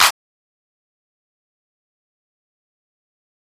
Clap 2.wav